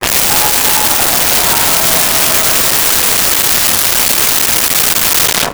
Large Crowd Applause 03
Large Crowd Applause 03.wav